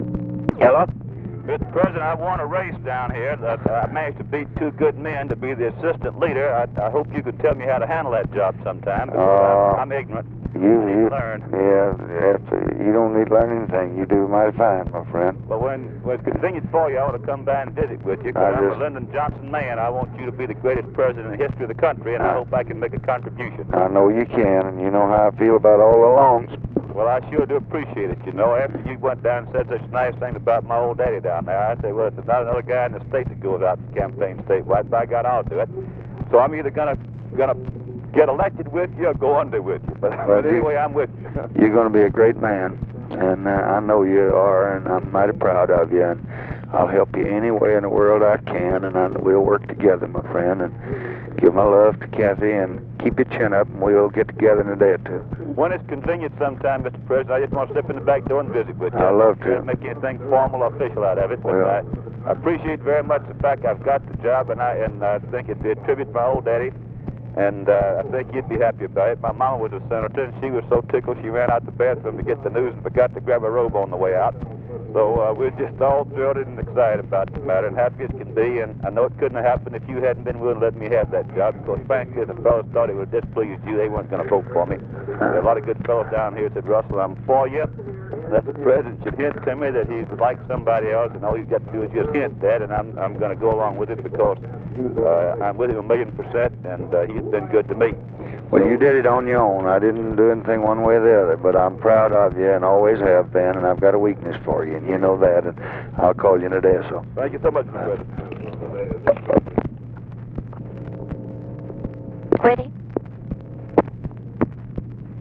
Conversation with RUSSELL LONG, January 4, 1965
Secret White House Tapes